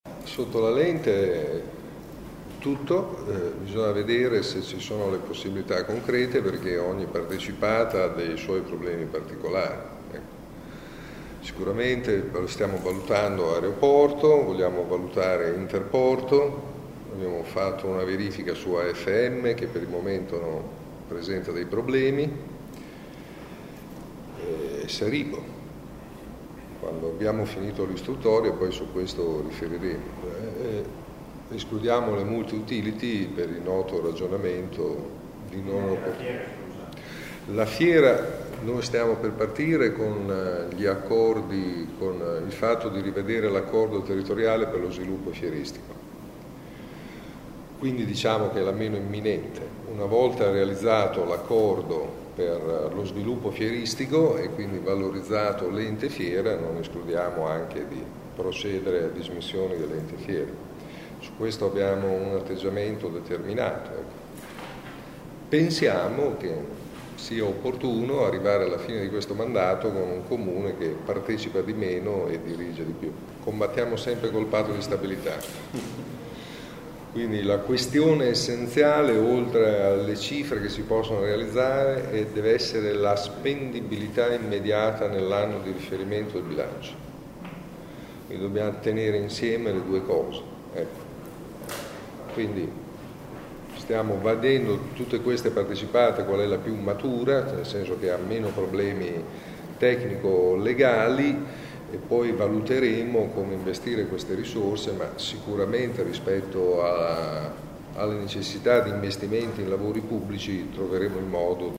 Le prime quote da cedere potrebbero essere quelle dell’Aeroporto. Ascolta il sindaco: